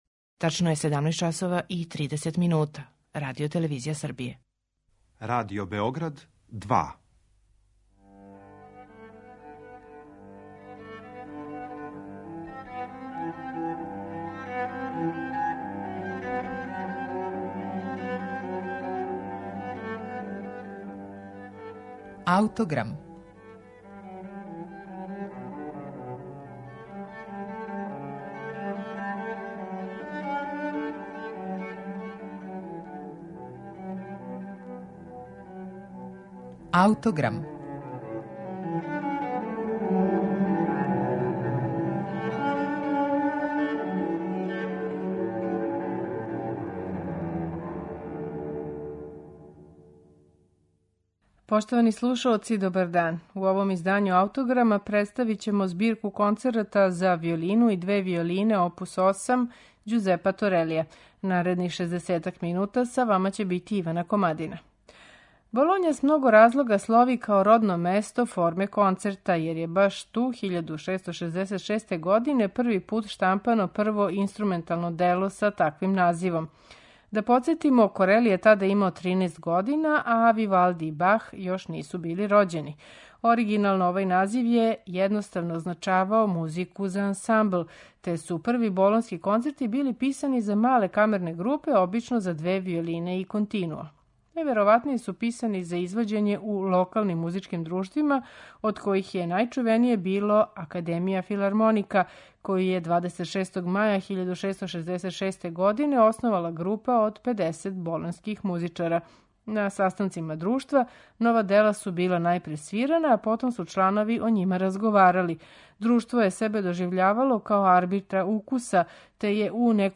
На снимку који ћемо чути у вечерашњем Аутограму басовска деоница следи болоњску праксу Торелијевог доба, у којој се виолончело комбинује са бас виолином, већим инструментом истог тонског опсега. У овим концертима Торели је у саставу басо континуа предвидео камерне оргуље, уз могућност додавања теорбе.